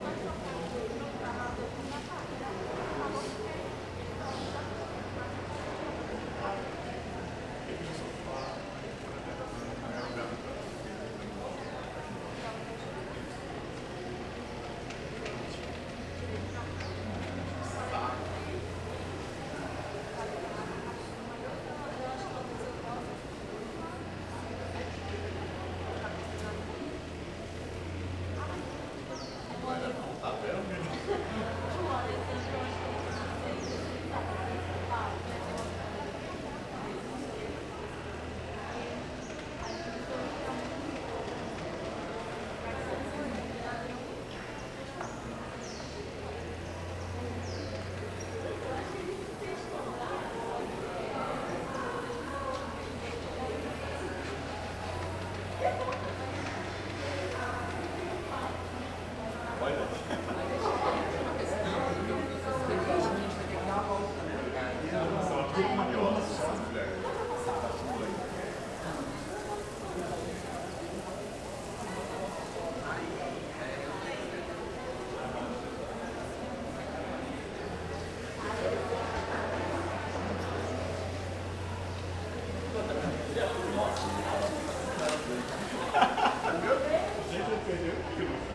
CSC-04-058-LE - Ambiencia unb espaco semiaberto com pessoas, cortador de grama e transito longe.wav